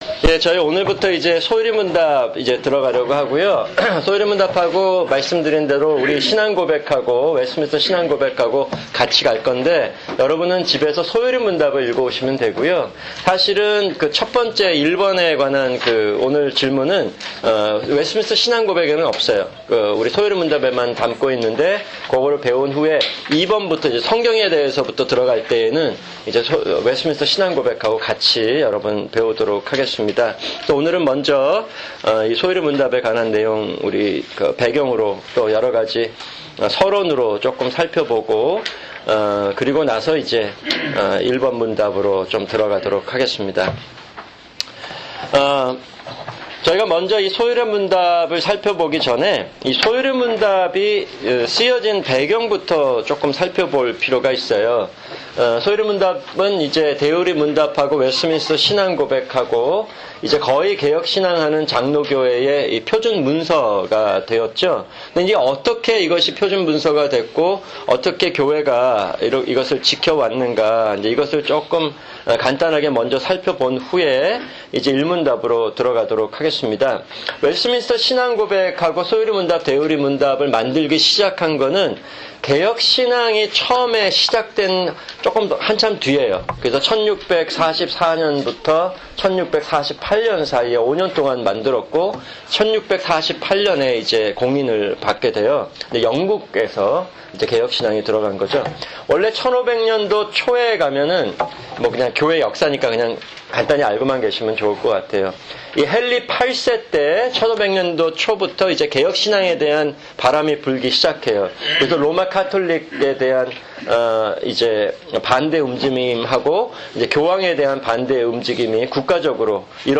[주일 성경공부] 소요리문답-1문답(1)